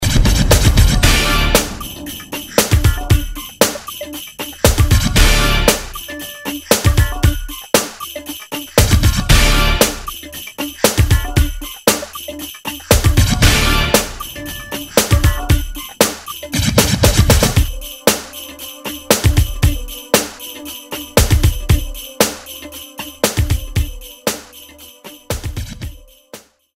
You can hear a subtle use of part of the RAM2 00